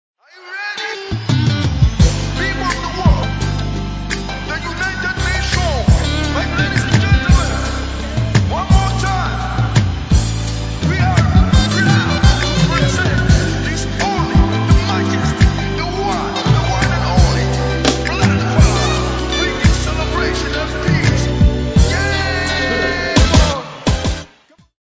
moderan r&b, uvod akustična gitara